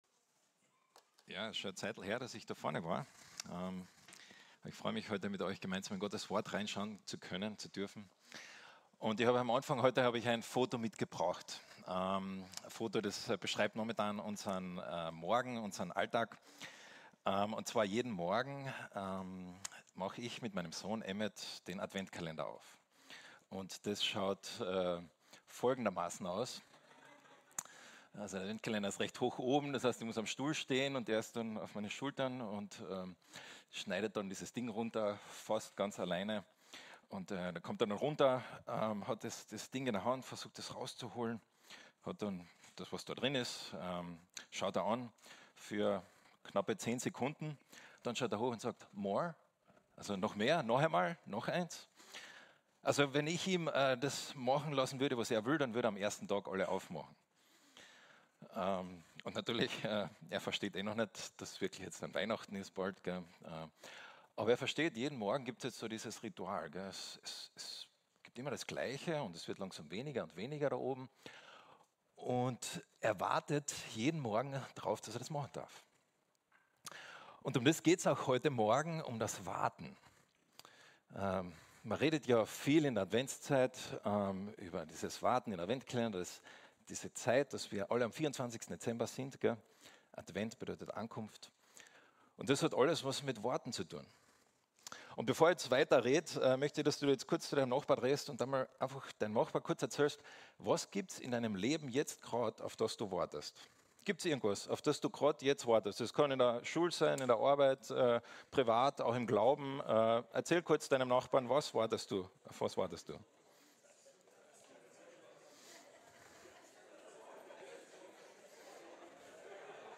Current preaching series – FEG Klagenfurt